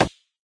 woodplastic.ogg